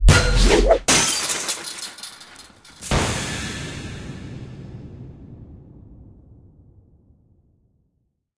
rooftopDoorSmash.ogg